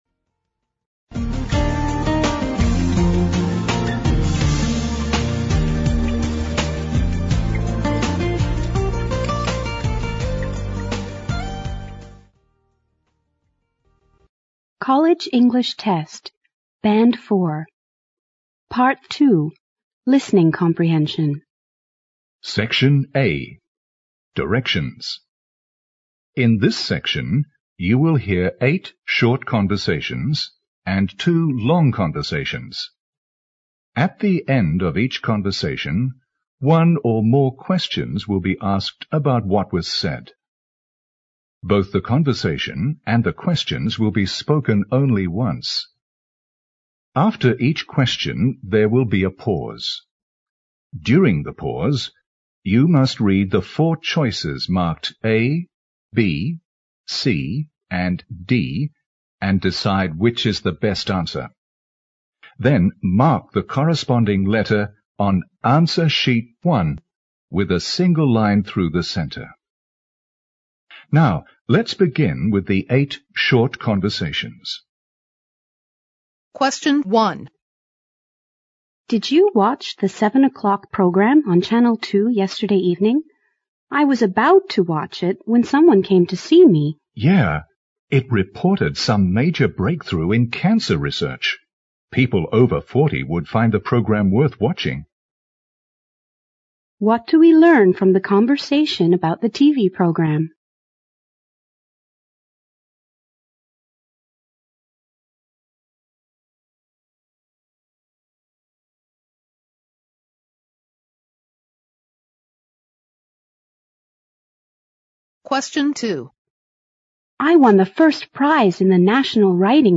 Part II Listening Comprehension (30 minutes)